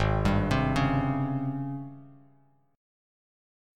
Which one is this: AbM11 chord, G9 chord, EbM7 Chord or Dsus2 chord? AbM11 chord